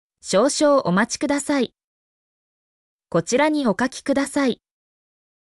mp3-output-ttsfreedotcom-4_G1RThbub.mp3